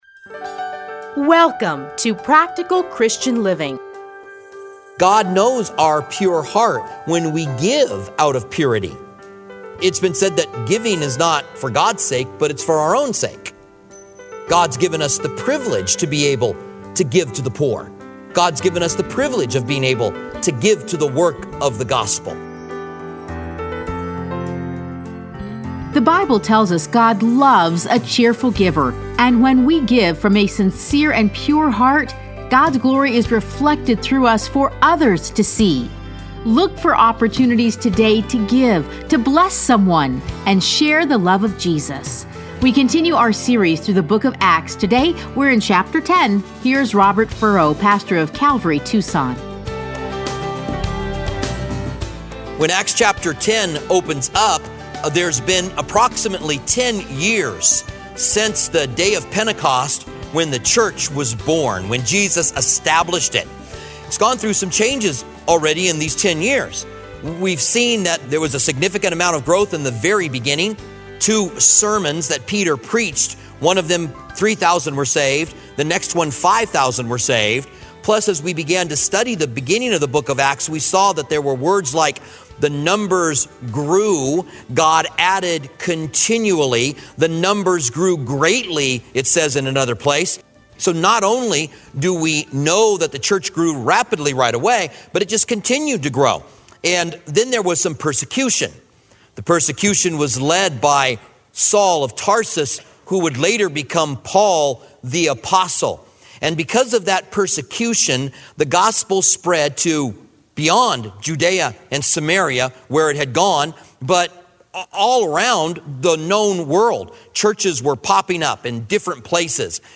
Listen to a teaching from Acts 10.